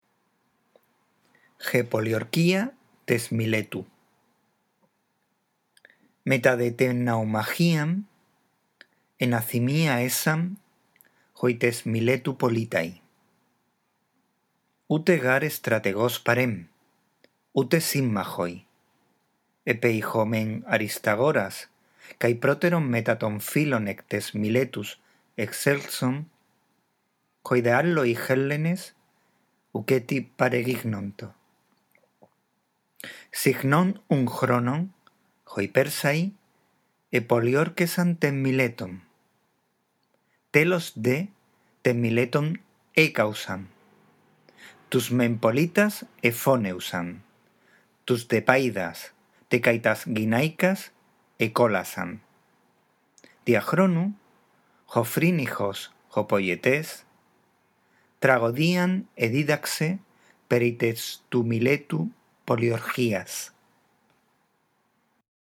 Antes de seguir adelante, escucha atentamente la lectura de este texto, te ayudará a comprenderlo mejor.